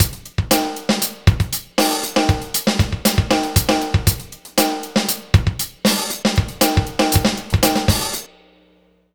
Gliss 2fer 2 Drumz.wav